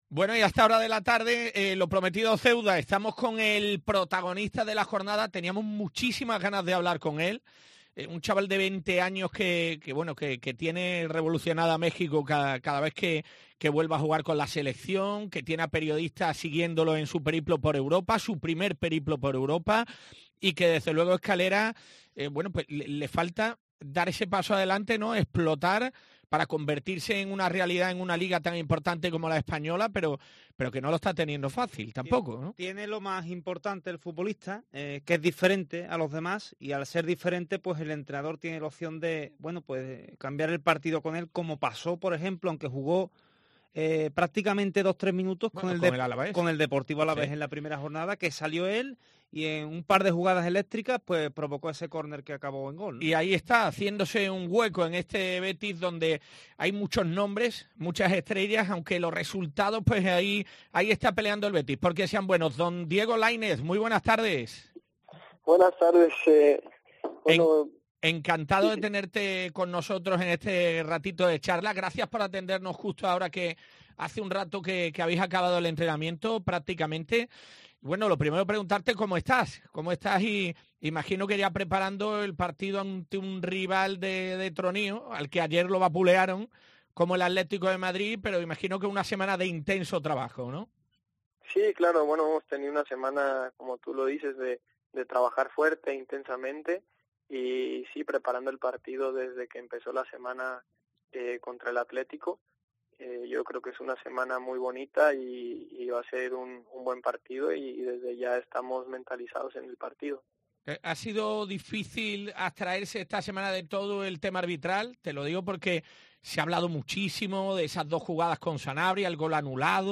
En este enlace puedes escuchar al completo la entrevista de este jueves en el 105.8 de la FM.